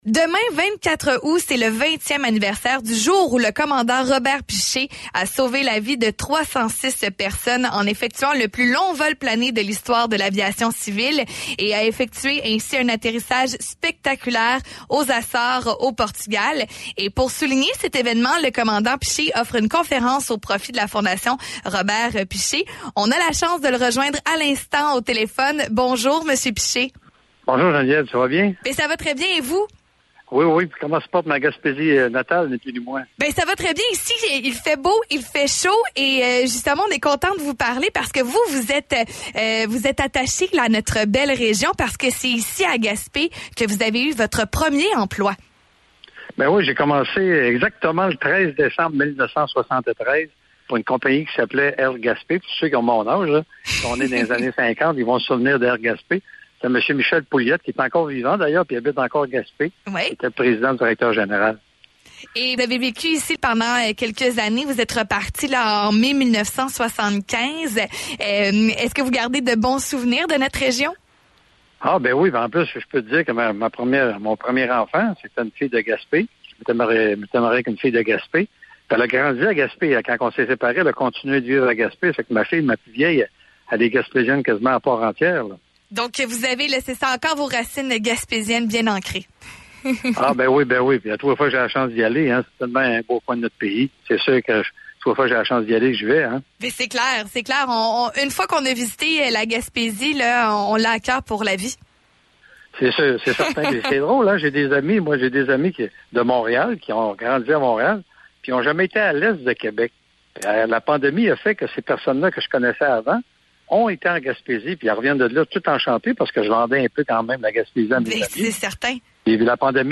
entrevueRobertPiche.mp3